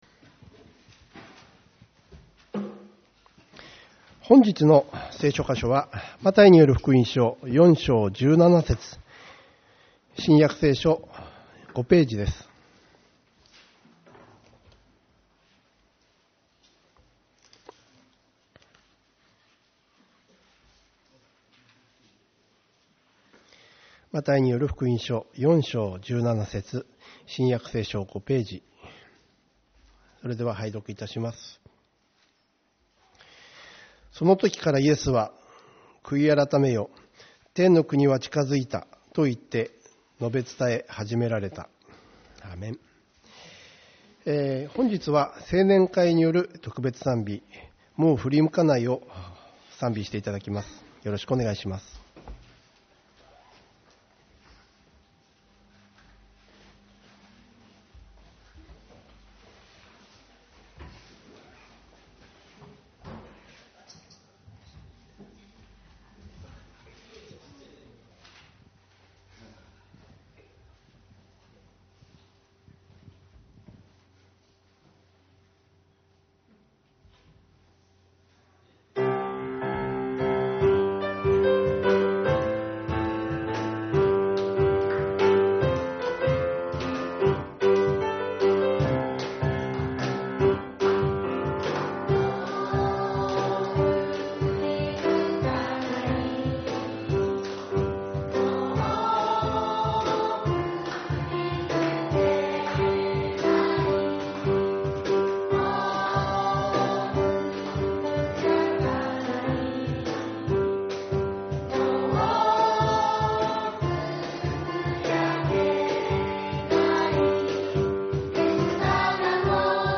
1月18日 主日礼拝 「悔い改めよ、天の国は近づいた」マタイによる福音書4.17